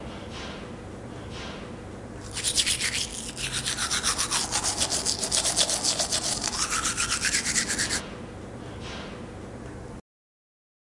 刀片大
描述：把大刀从刀鞘里拔出来再放回去